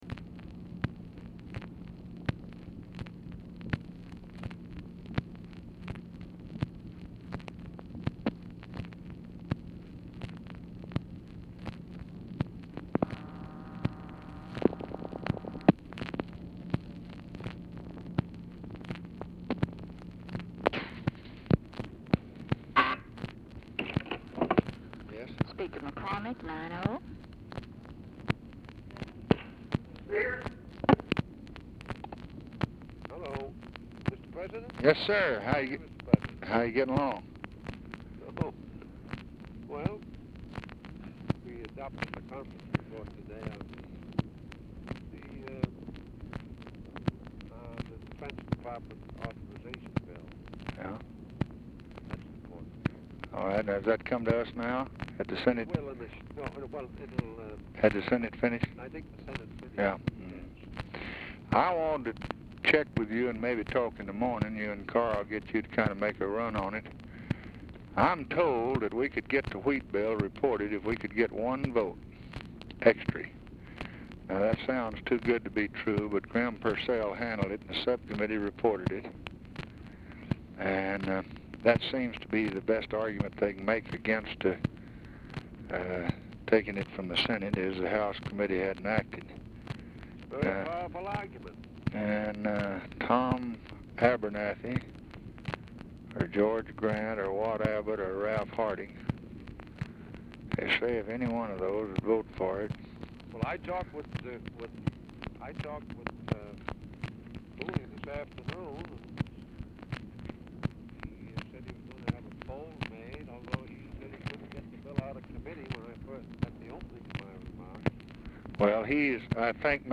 MACHINE NOISE PRECEDES CONVERSATION; MCCORMACK DIFFICULT TO HEAR
Format Dictation belt
Specific Item Type Telephone conversation Subject Agriculture Civil Rights Congressional Relations Legislation Welfare And War On Poverty